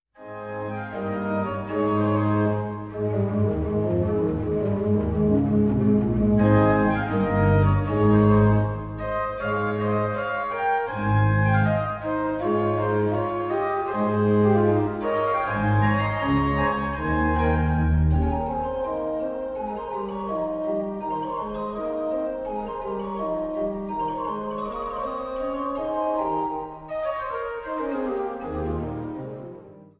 Großengottern, Waltershausen, Altenburg, Eisenach